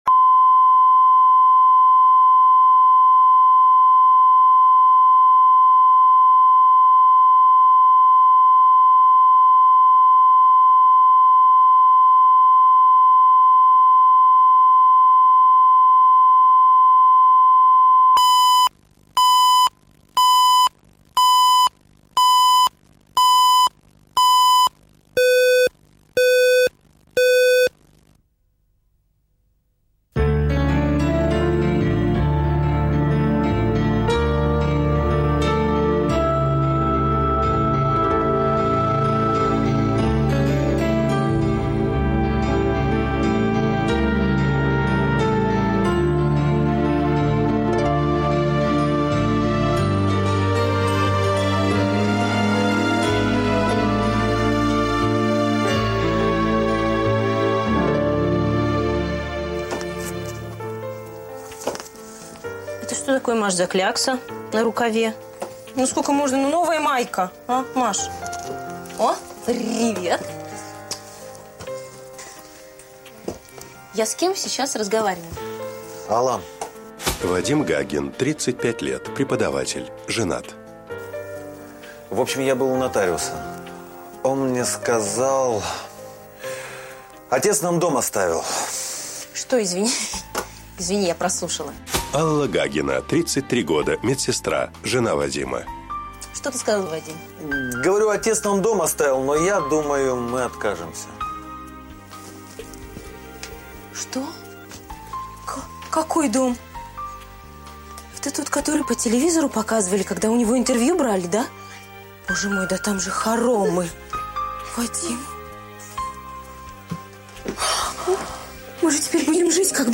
Аудиокнига Наследство с сюрпризом | Библиотека аудиокниг